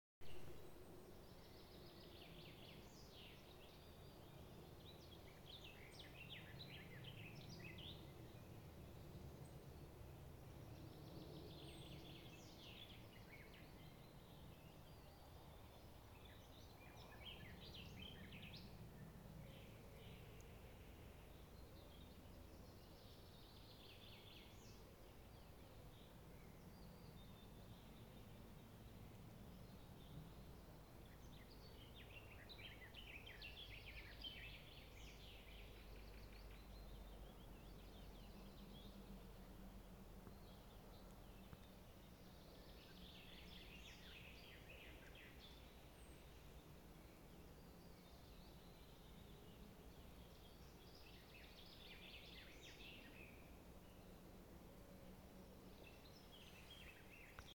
Putns (nenoteikts), Aves sp.
СтатусСлышен голос, крики